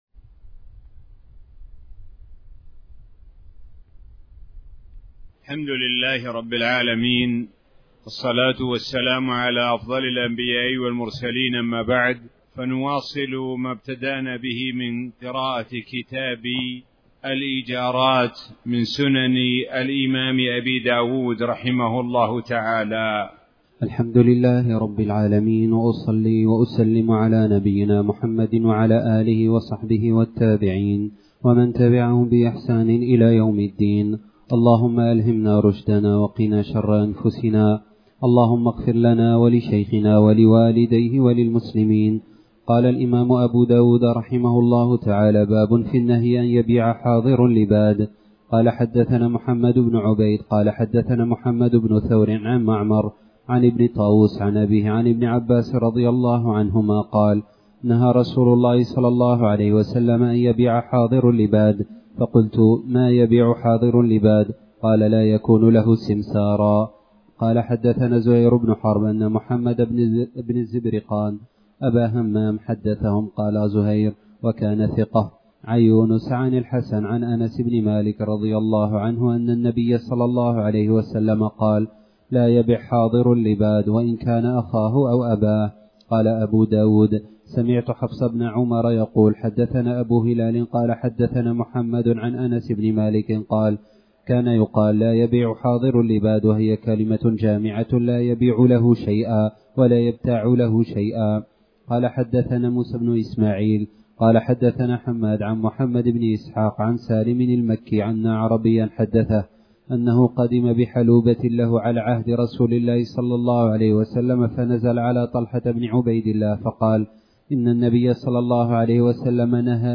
تاريخ النشر ٢٣ ذو الحجة ١٤٣٩ هـ المكان: المسجد الحرام الشيخ: معالي الشيخ د. سعد بن ناصر الشثري معالي الشيخ د. سعد بن ناصر الشثري بيع حاضر لباد